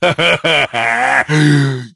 bull_lead_vo_02.ogg